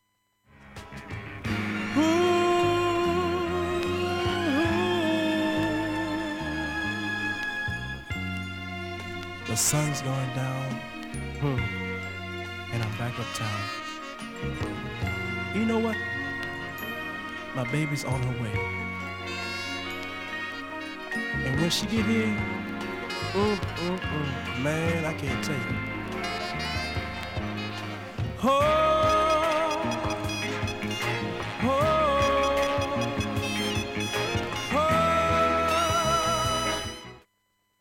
a-1終わりフェイドアウト部から
１８回プツ出ますがごくかすかです。